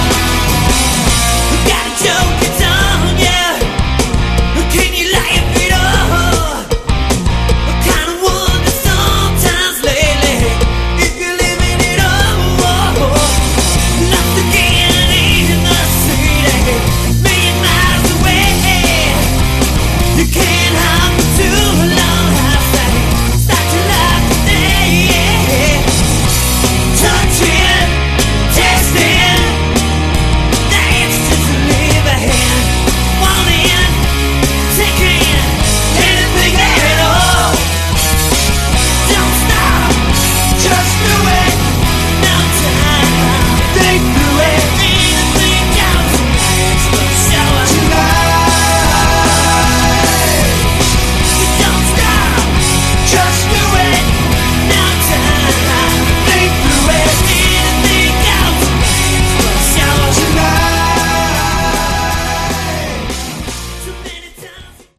Category: Sleaze Glam
lead vocals
guitars
bass
drums